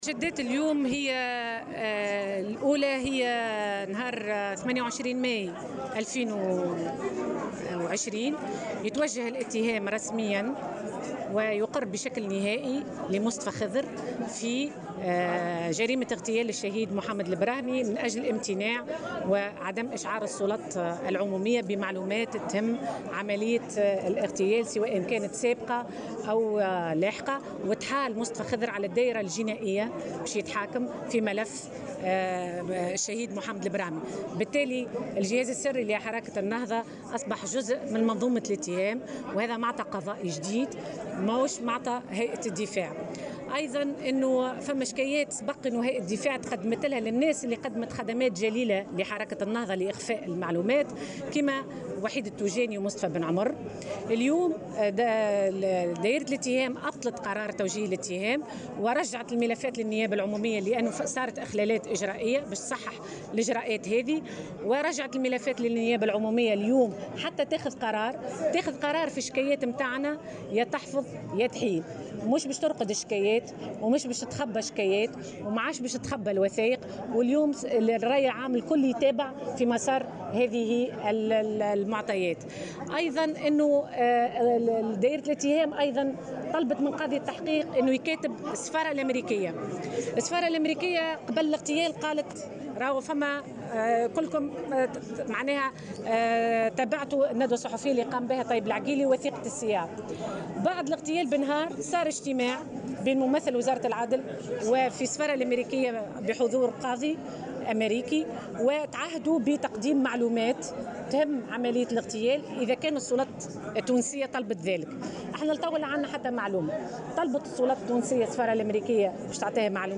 وأضافت على هامش ندوة صحفية عقدتها هيئة الدفاع اليوم، أنه تمت إحالته على الدائرة الجنائية لمحاكمته في هذا الملف، مشيرة إلى أن الجهاز السري لحركة النهضة أصبح جزءا من منظومة الاتهام، وفق قولها.